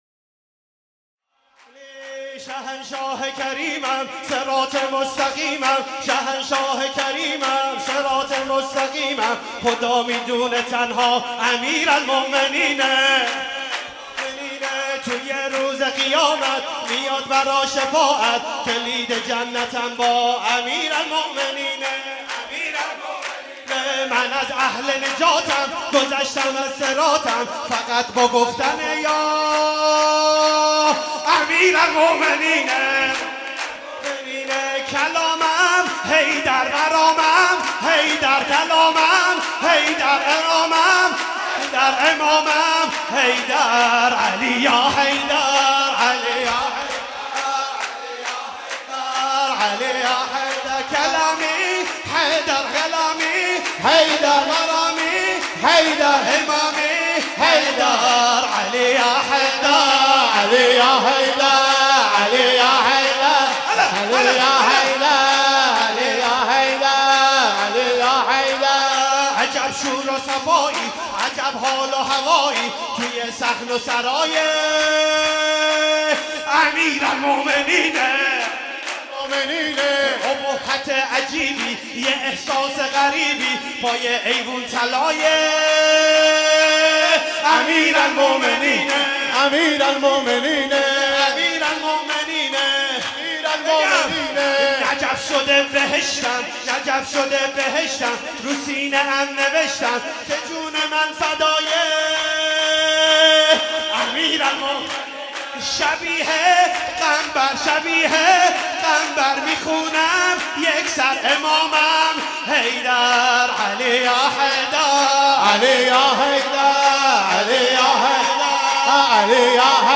ولادت امام علی (ع)
مداحی